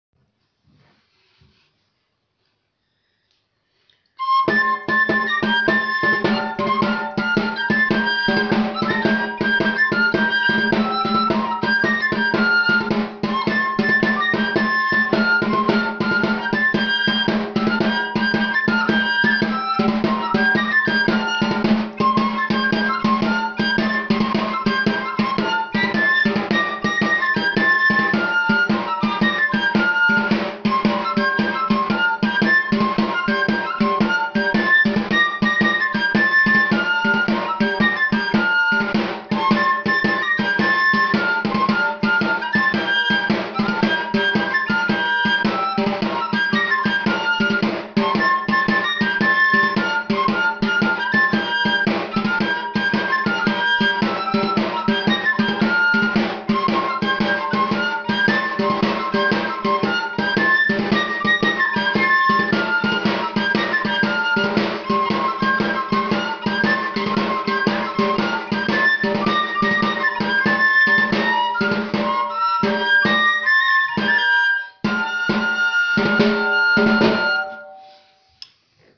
Arantzako dantza luzea: neska-dantza (mp3)
arantzako-dantza-luzea-neska-dantza-mp3